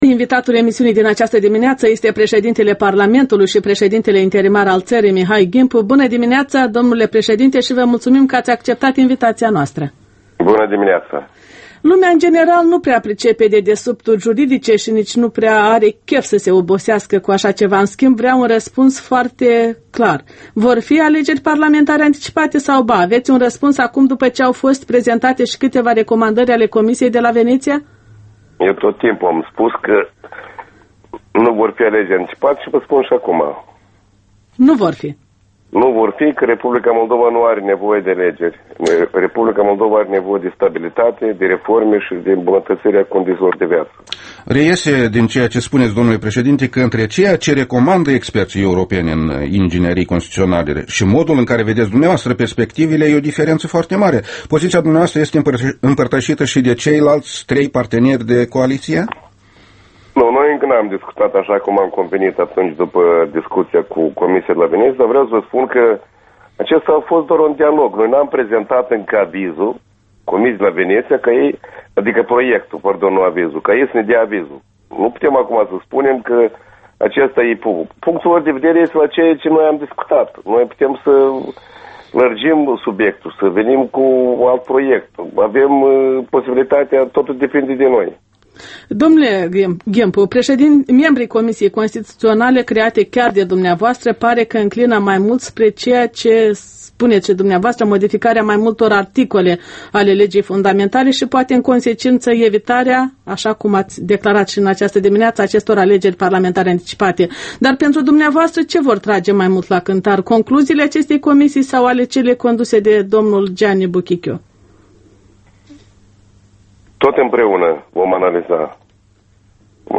Interviul matinal EL: cu președintele Mihai Ghimpu